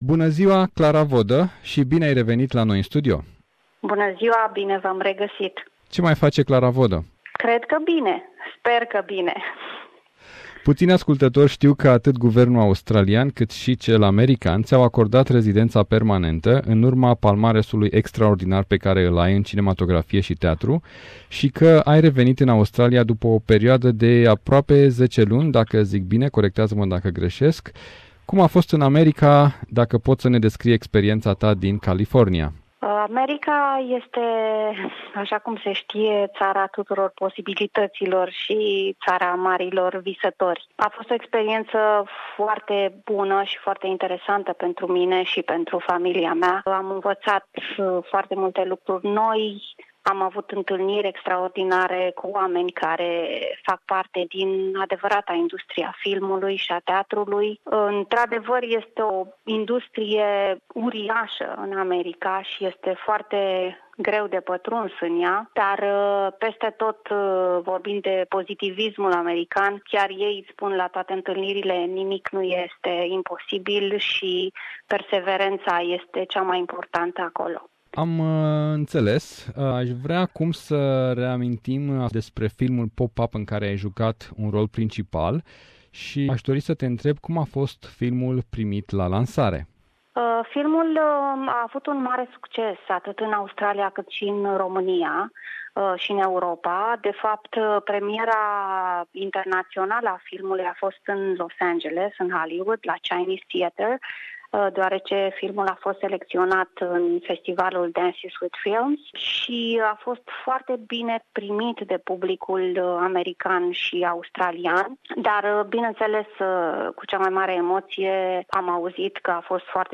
Interviu telefonic pentru SBS.